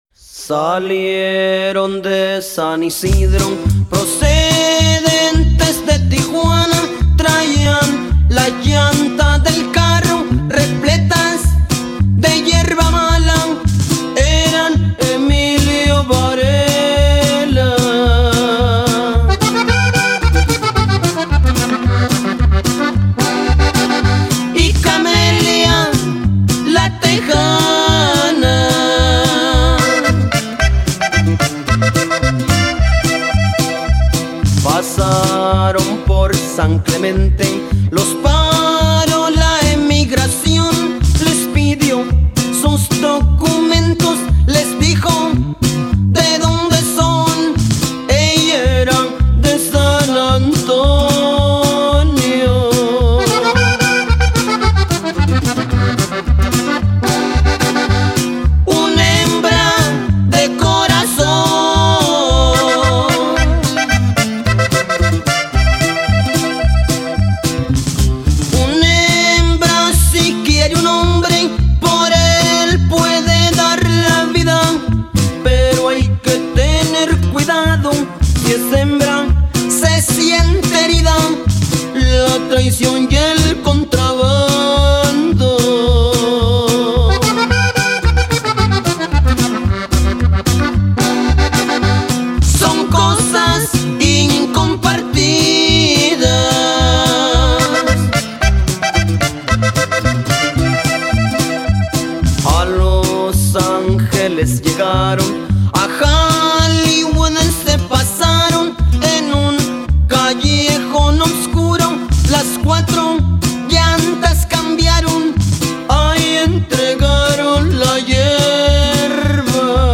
(drug smuggling song)    Sing Out!
LATIN MUSIC